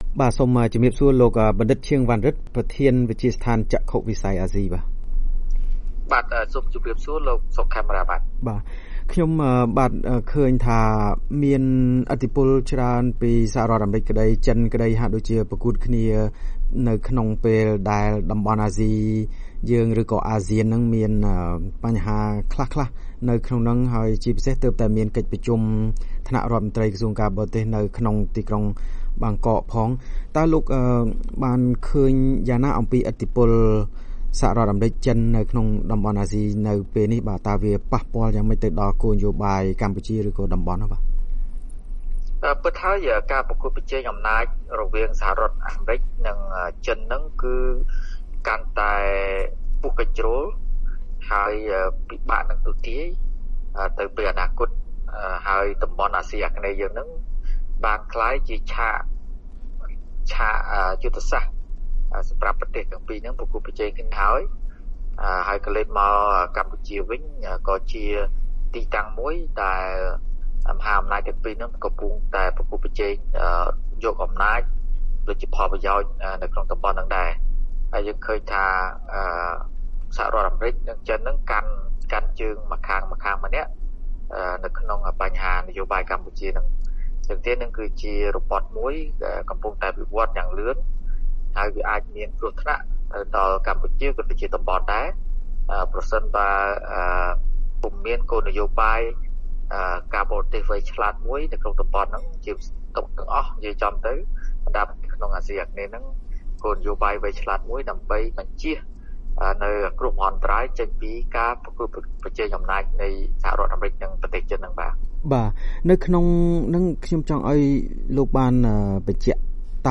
បទសម្ភាសន៍ VOA៖ អ្នកវិភាគថាតំបន់អាស៊ីប្រឈមនឹងឥទ្ធិពលមហាអំណាចដ៏គ្រោះថ្នាក់